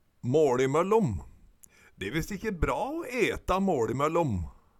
måLimøllom - Numedalsmål (en-US)
DIALEKTORD PÅ NORMERT NORSK måLimøllom mellom måltida Eksempel på bruk De e visst ikkje bra å eta måLimøllom Tilleggsopplysningar Kjelde